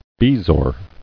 [be·zoar]